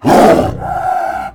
CosmicRageSounds / ogg / general / combat / creatures / tiger / she / taunt1.ogg